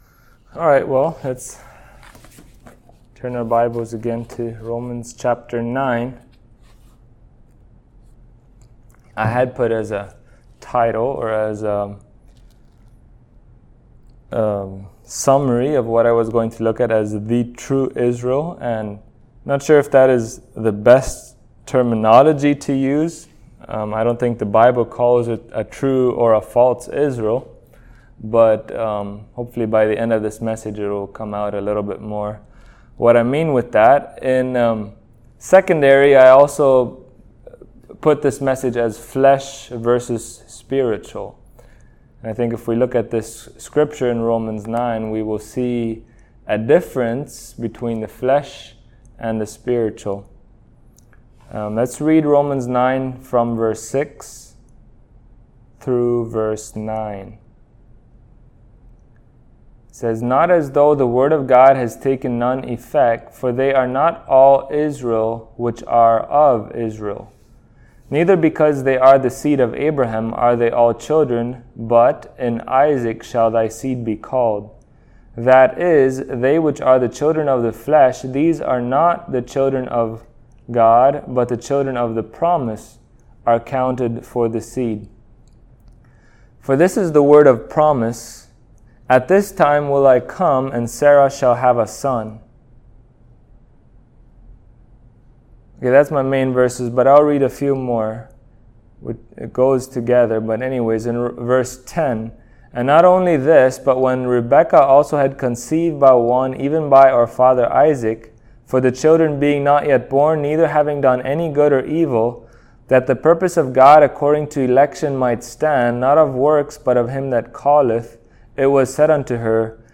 Passage: Romans 9:6 Service Type: Sunday Morning